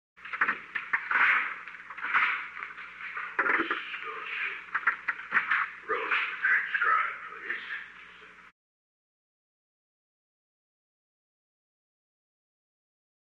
Secret White House Tapes
Location: Oval Office
The President met with an unknown man.